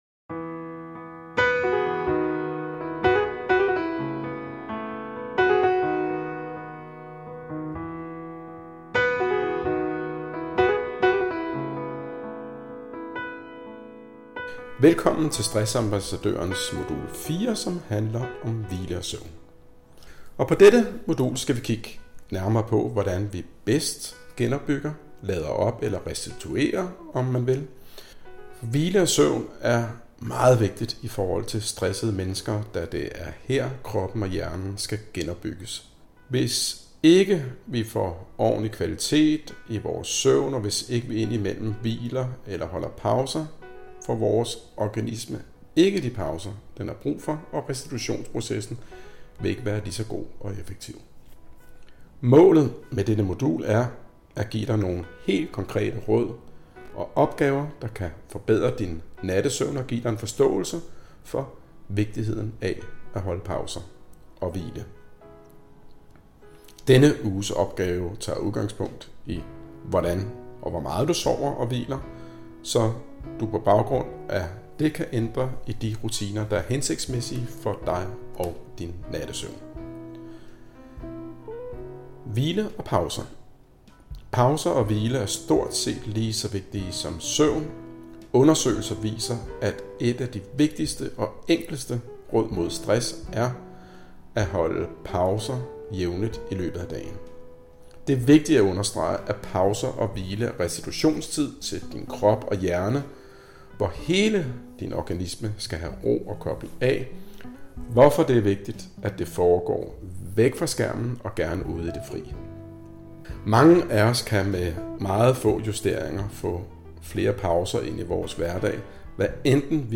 Artikel-Indlaest-Modul-4-tekst-indl‘st-podcast.mp3